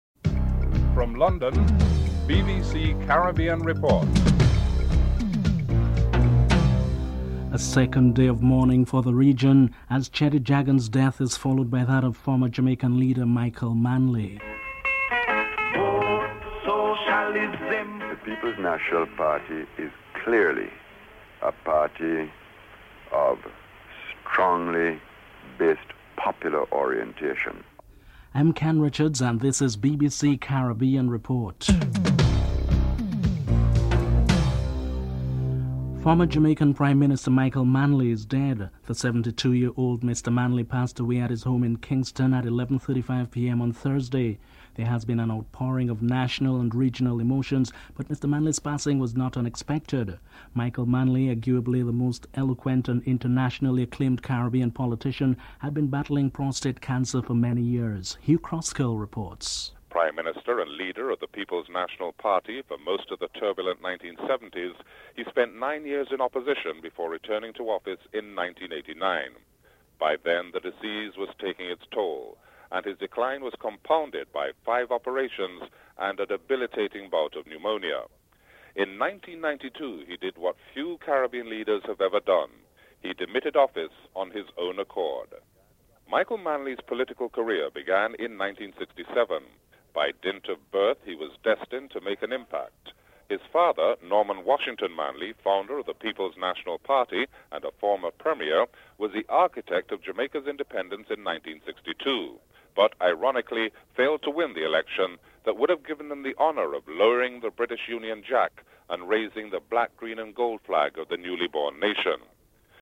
In the final segment, annonymous Jamaican people at home and in New York express their feelings on the death of Michael Manley and a past interview with him is aired.